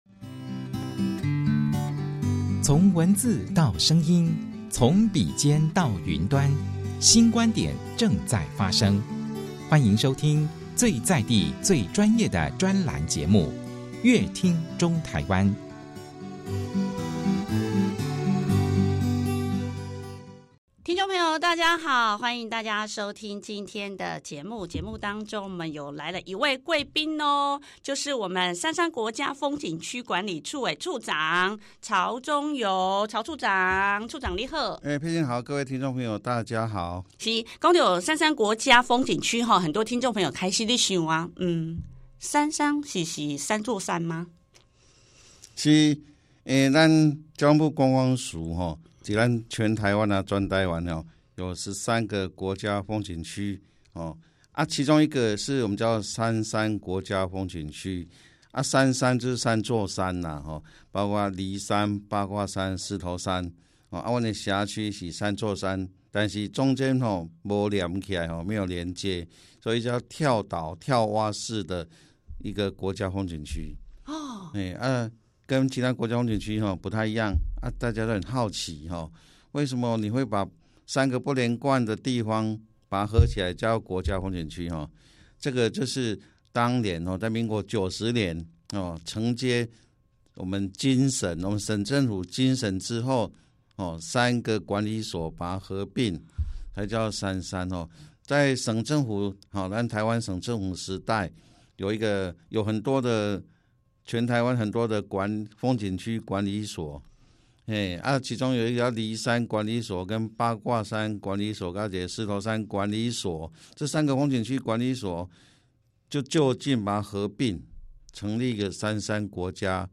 參山國家風景區食宿遊購好滿足 參山國家風景區經營管理範圍，涵蓋獅頭山、梨山及八卦山三處風景區，曹處長在專訪中跟聽眾說明參山國家風景區管理處由來。參山國家風景區玩法非常多元與豐富之外，食宿遊購樣樣滿足，歡迎大家來參山國家風景區轄區旅遊，順遊周邊景點，以二水鐵道櫻花活動成功的企劃為例，結合在地繁榮台灣，想知道更多節目精彩的內容，請鎖定本集精彩專訪。